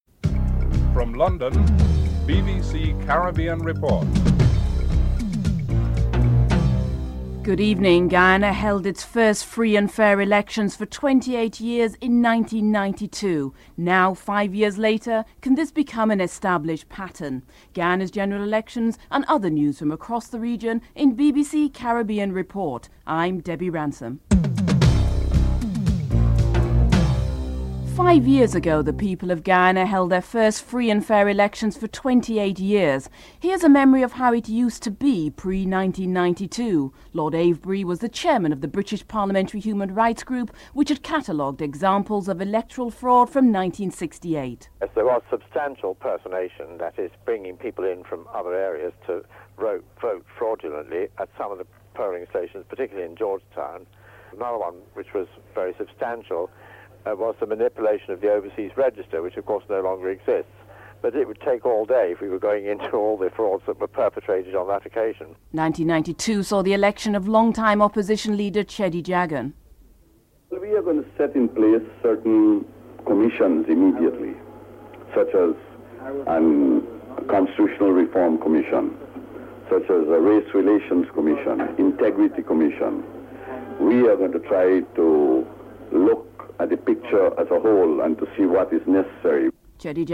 1. Headlines (00:00-00:27)